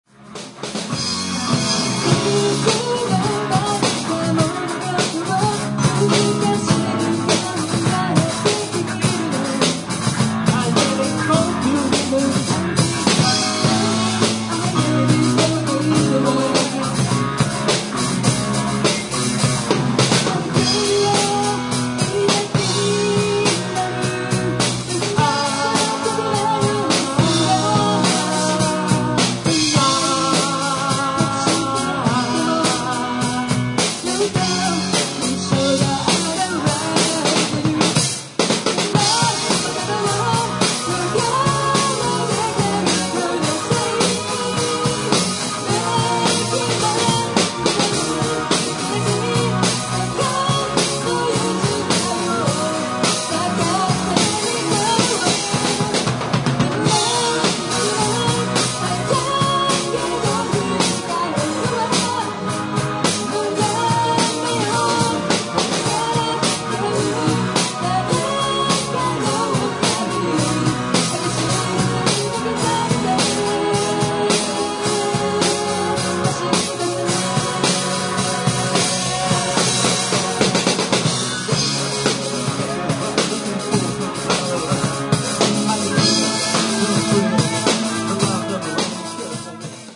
三声ハーモニー
本番は、PAが弱いハコなので、演奏もコーラスも音量バランスはイマイチでしたが、内容的にはなかなかの出来となりました。
keyboard,chorus
drums
guitar
bass